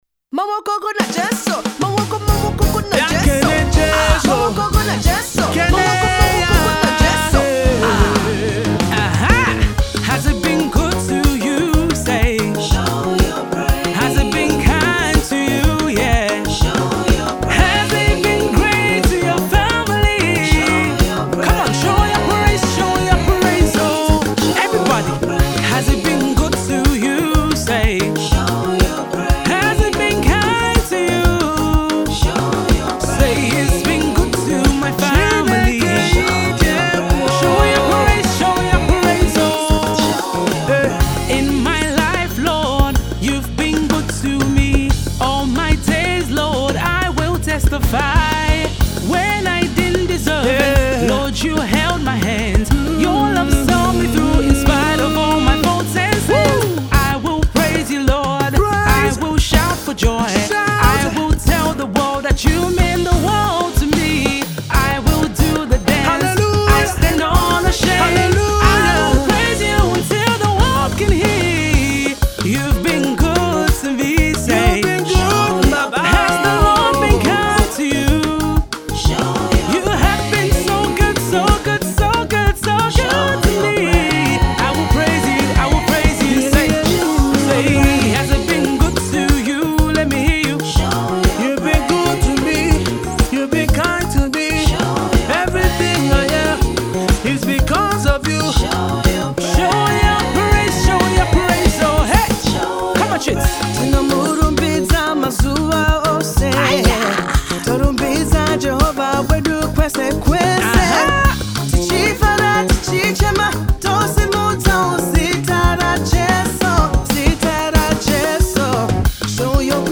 UK Based Nigerian Gospel Artist